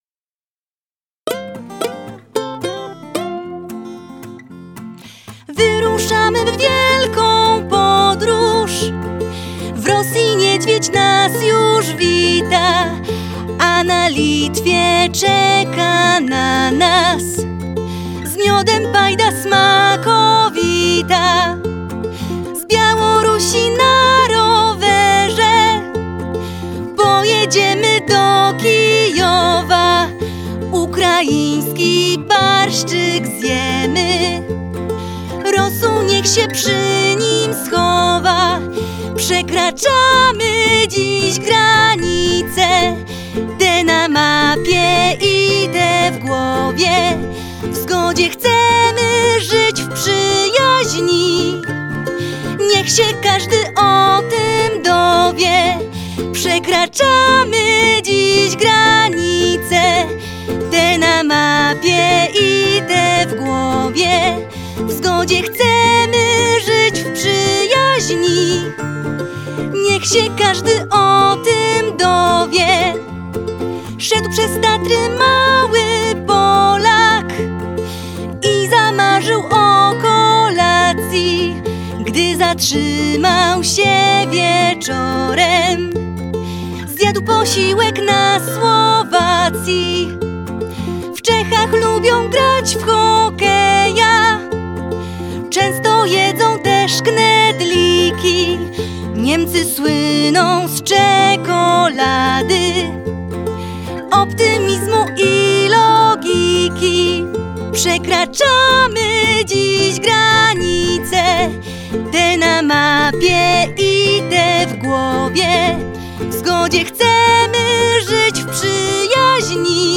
Przekraczamy_dziś_granice_voc.mp3